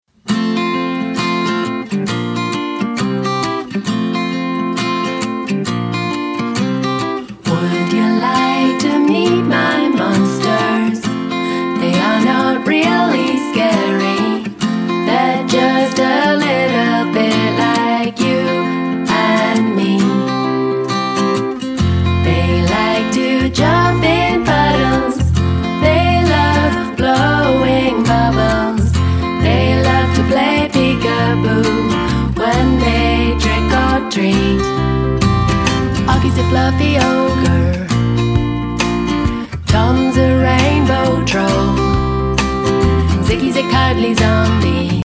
With three fun and silly songs for Halloween
not-too-scary spooky season EP